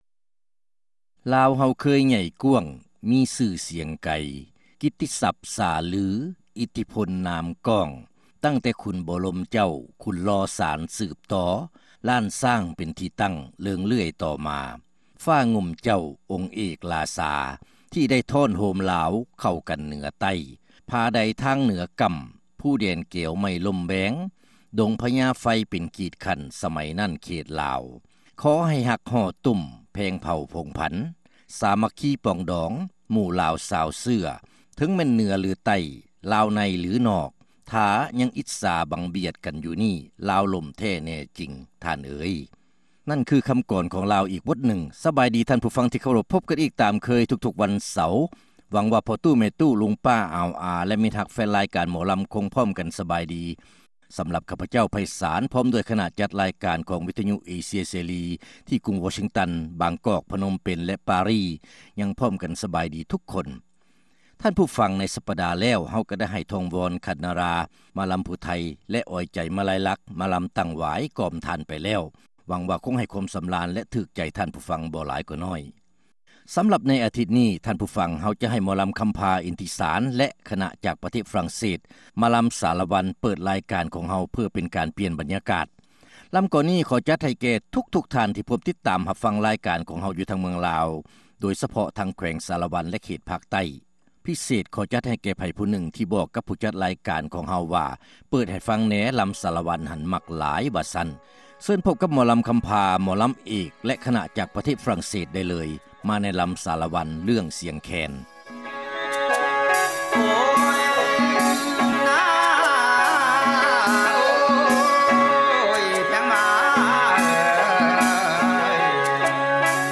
ຣາຍການໜໍລຳ ປະຈຳສັປະດາ ວັນທີ 5 ເດືອນ ພືສະພາ ປີ 20